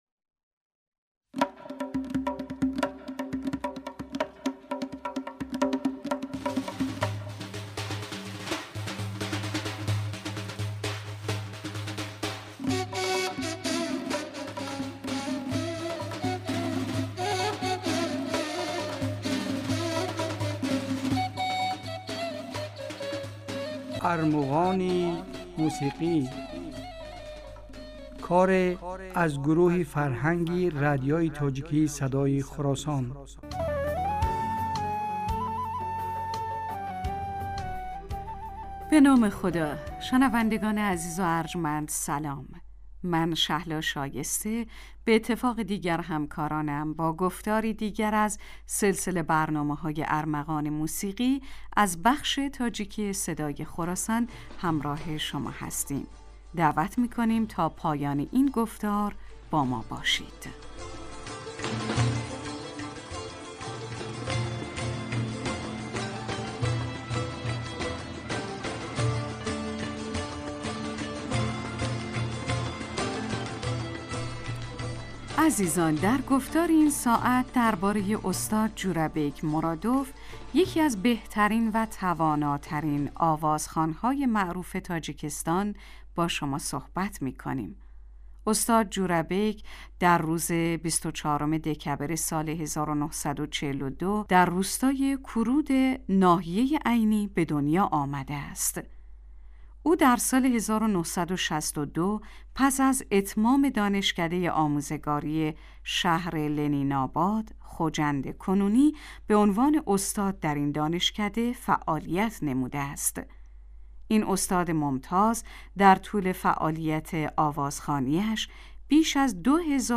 Армуғони мусиқӣ асаре аз гурӯҳи фарҳанги радиои тоҷикии Садои Хуросон аст.